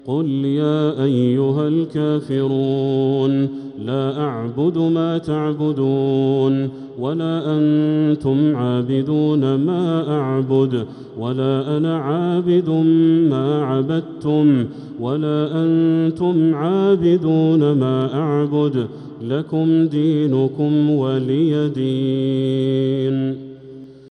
سورة الكافرون كاملة | 6 رمضان 1446هـ > السور المكتملة للشيخ بدر التركي من الحرم المكي 🕋 > السور المكتملة 🕋 > المزيد - تلاوات الحرمين